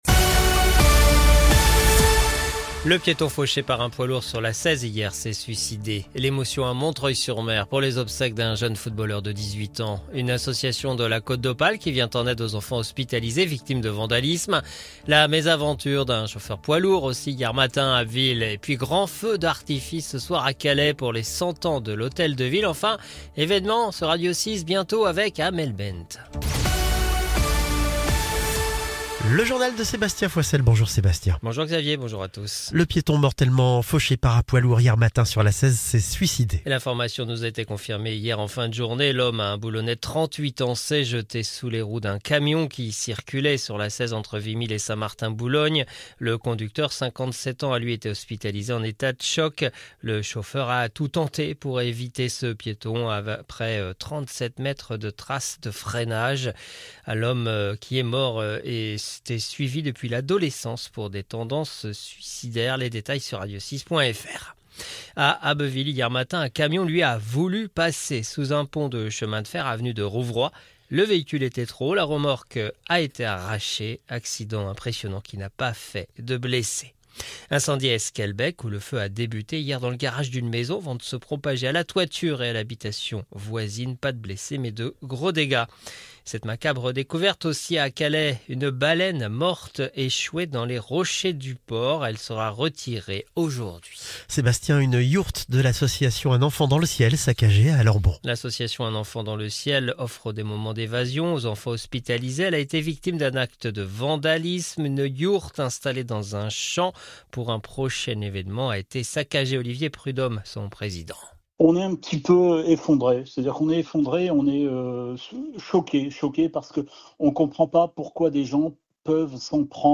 Le journal de vendredi 11 avril 2025